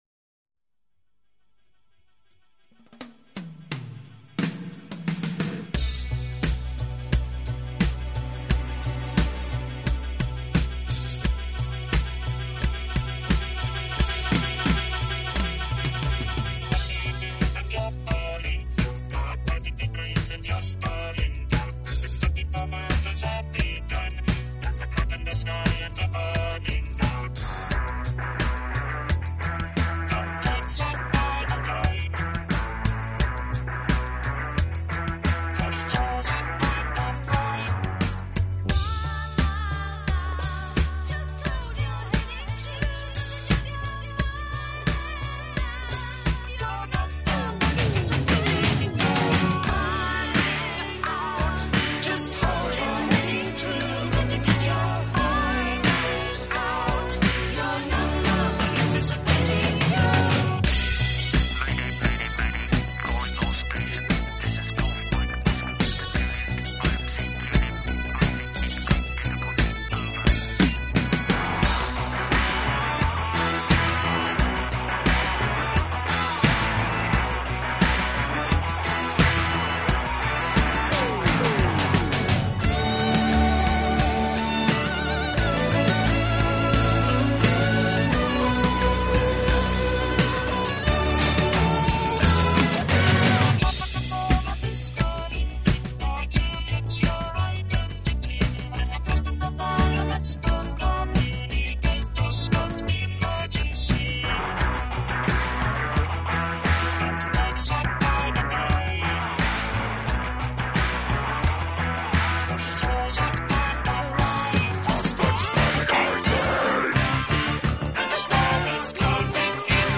音乐流派：new age